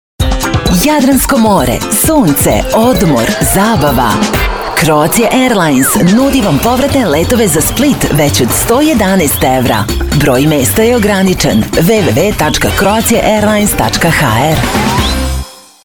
0821Croatia_Airlines_-_commercial.mp3